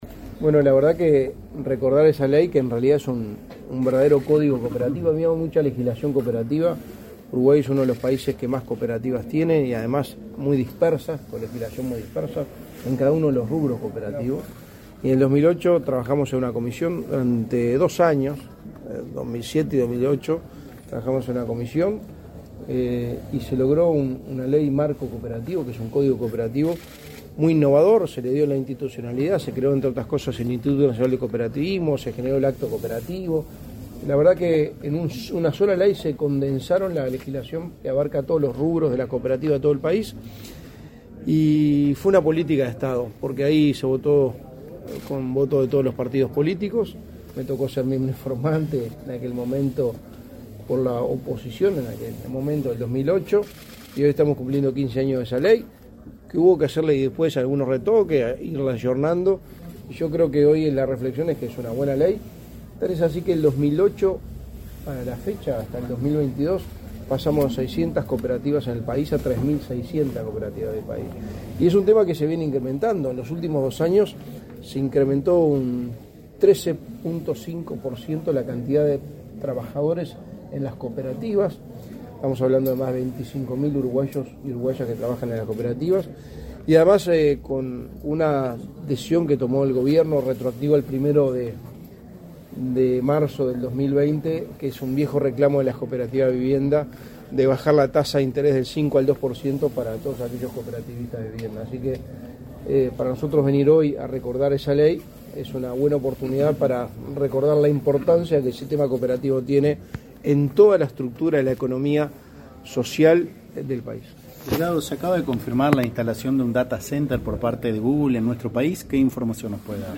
Declaraciones a la prensa del secretario de la Presidencia, Álvaro Delgado
En el marco del acto realizado por el Instituto Nacional del Cooperativismo (Inacoop), este 31 de octubre, por el 15.° aniversario de la promulgación